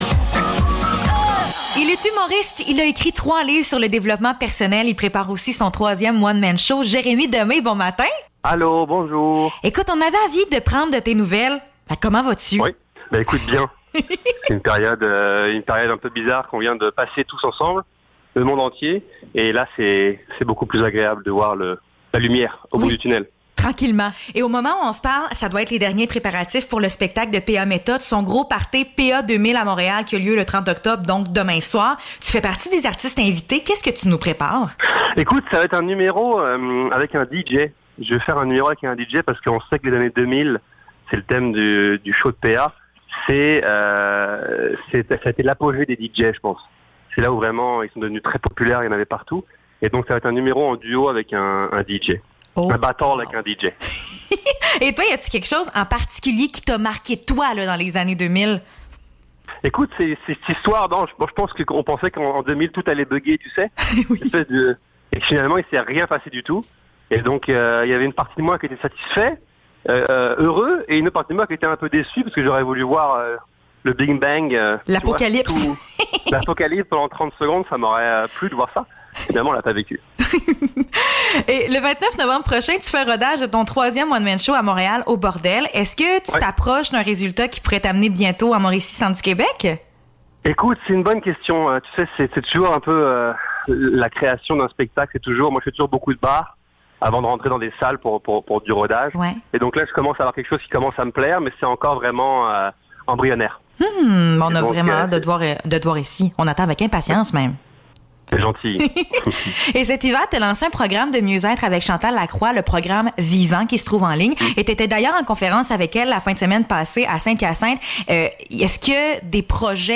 Entrevue avec l’humoriste Jérémy Demay (29 octobre 2021)